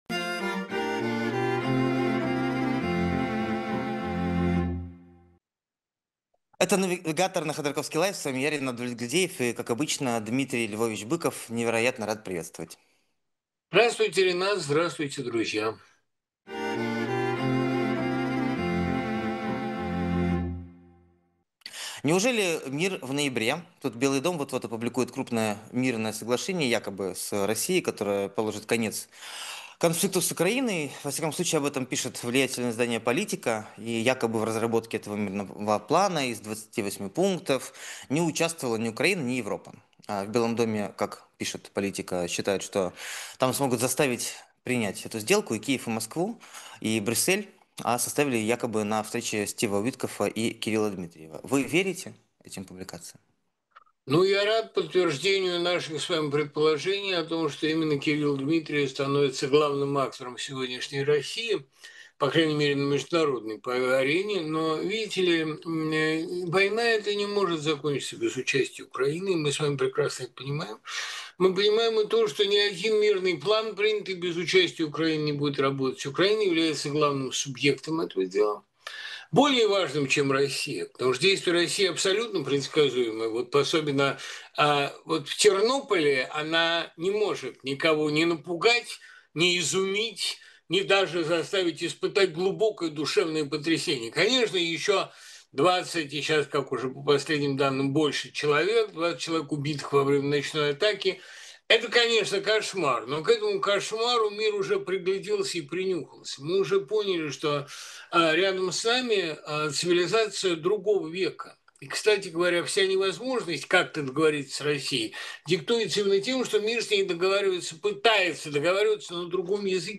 Дмитрий Быков поэт, писатель, журналист